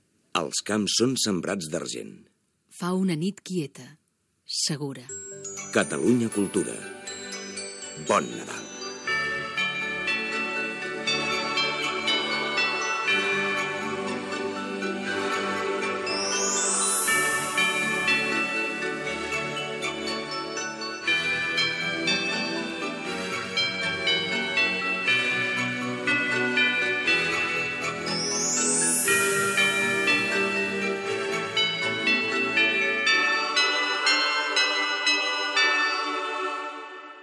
Indicatiu de Nadal de l'emissora